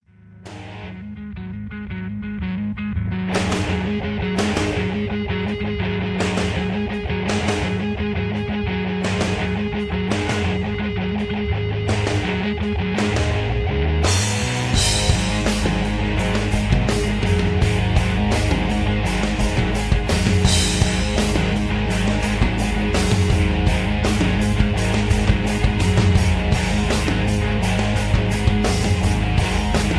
hip hop
rap